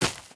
t_grass2.ogg